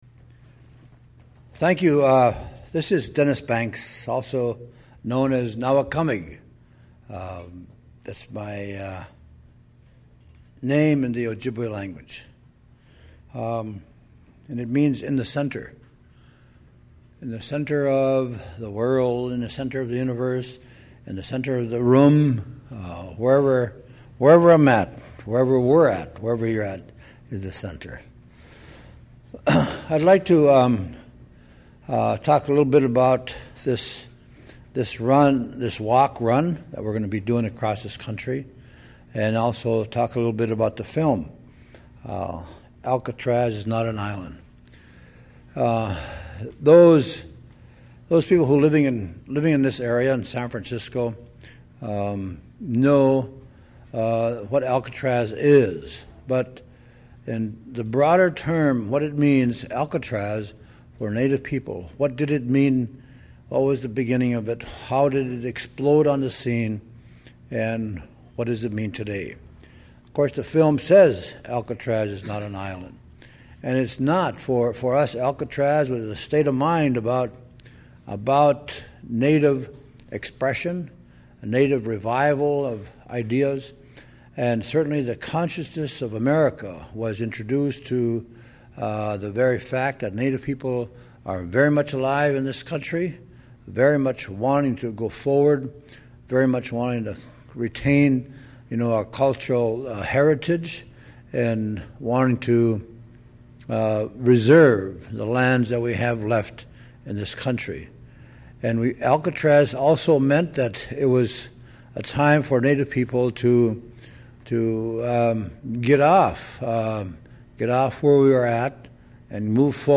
This is not broacast quality
Location Recorded: San Francisco